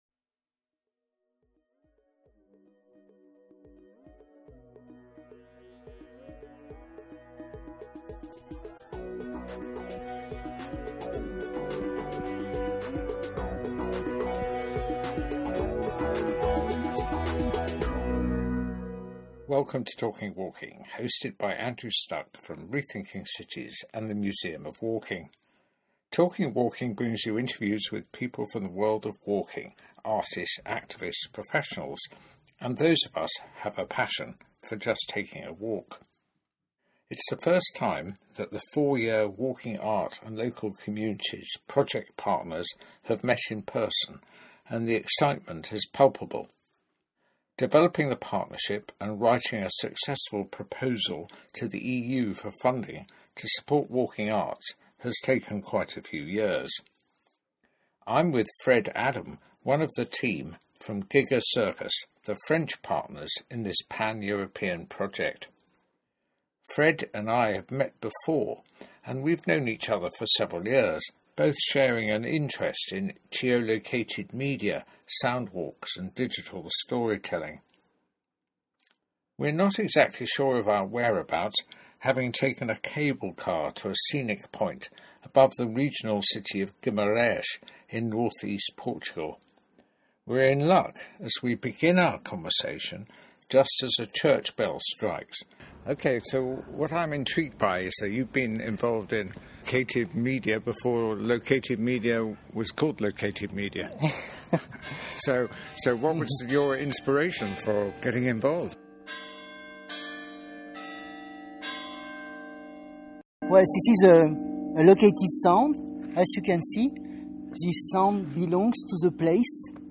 sound walks and digital storytelling They are not exactly sure of their whereabouts having taken a cable car to a scenic point above the regional city of Guimaraes in north east in Portugal. They are in luck as they begin their conversation just as a church bell strikes.